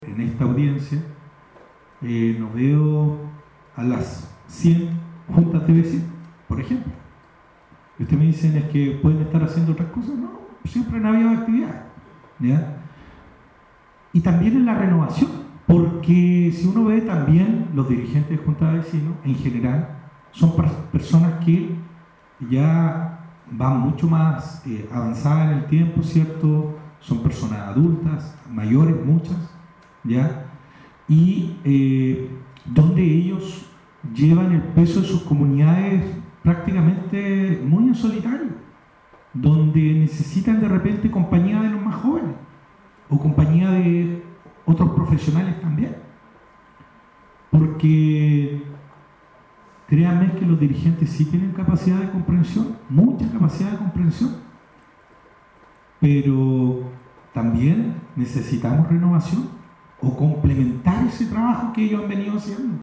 El alcalde de Ancud presentó sus argumentos frente a lo expuesto por las organizaciones además de su preocupación por la falta de participación de las juntas de vecinos, tanto urbanas como rurales.
Más de un centenar de personas participó de esta audiencia pública realizada en Ancud en torno a las amenazas al territorio de Chiloé por los proyectos de energía eólica y carreteras eléctricas.